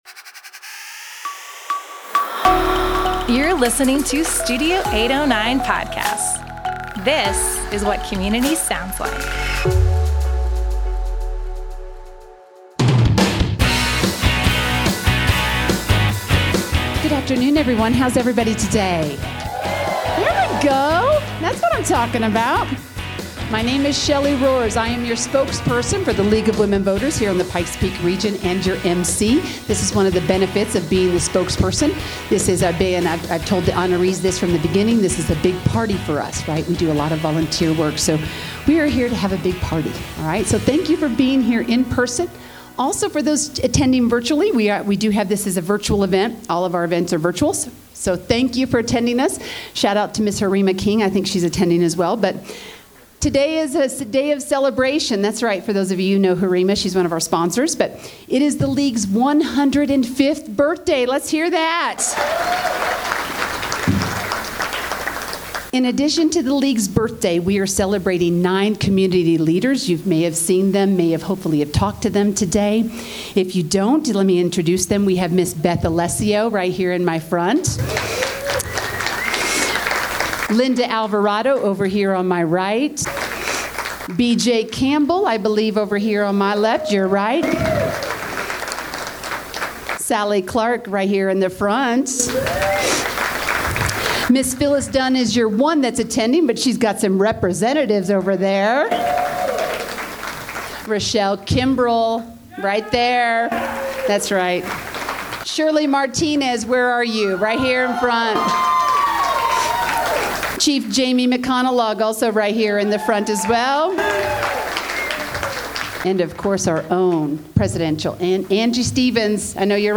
This is our annual fundraising event. We’re proud to share a replay in this episode.